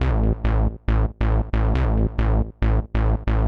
NASTY BASS-R.wav